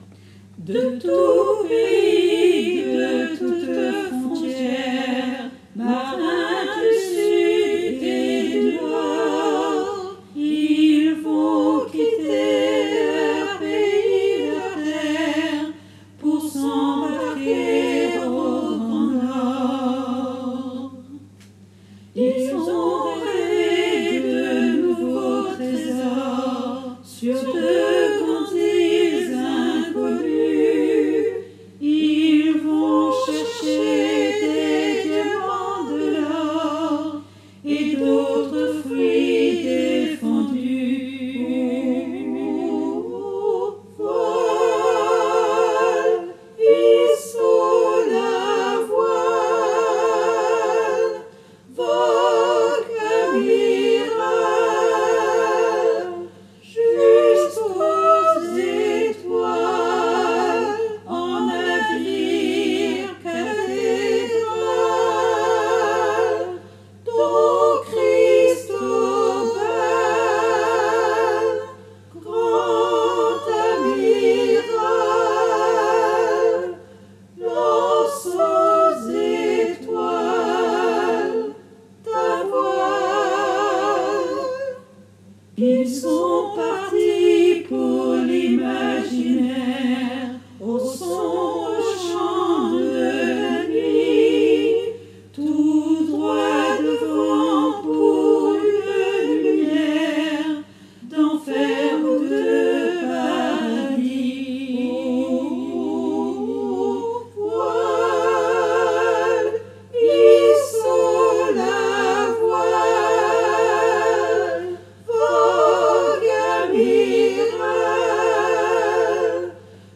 MP3 versions chantées
Toutes les voix